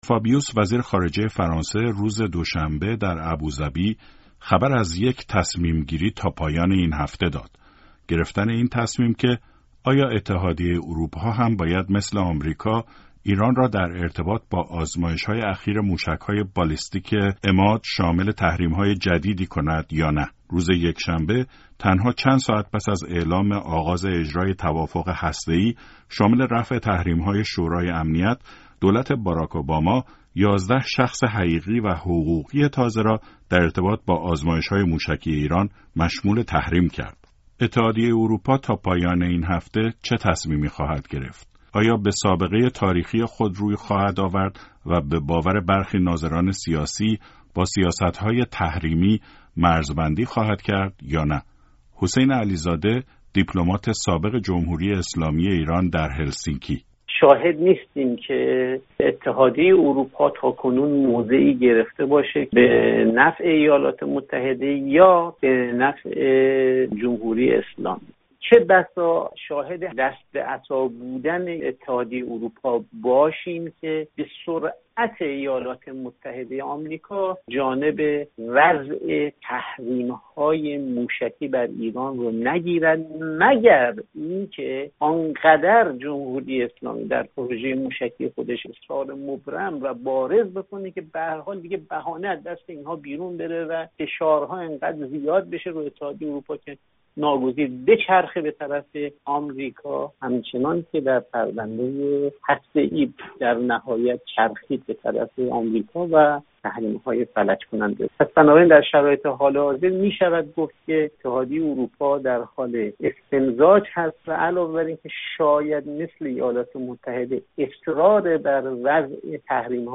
رادیوفردا گزارشی تحلیلی در این باره دارد.